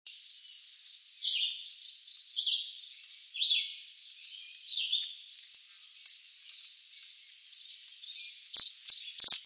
Chipping Sparrow
sparrow_chipping_654.wav